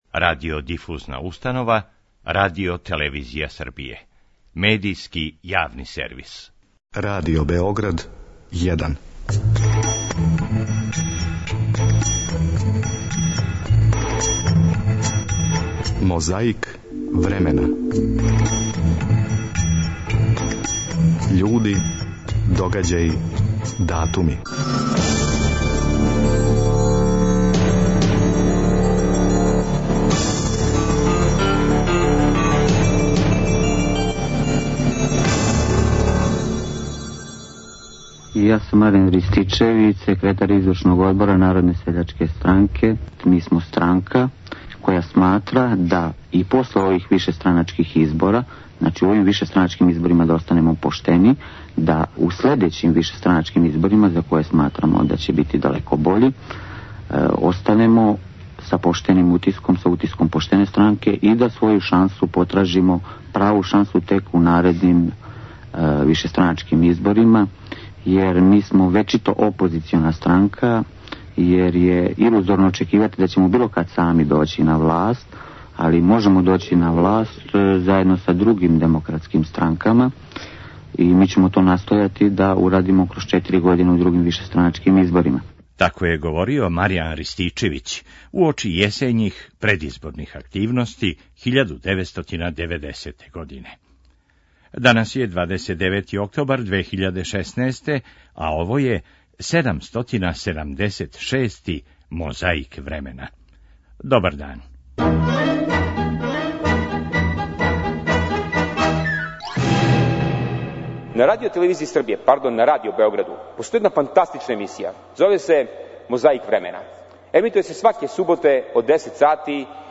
Ако нас будете пажљиво слушали, можете чути како Јосип Броз Тито пева на отварању 6. конгреса КПЈ 2. новембра 1952. године.
Подсећа на прошлост (културну, историјску, политичку, спортску и сваку другу) уз помоћ материјала из Тонског архива, Документације и библиотеке Радио Београда.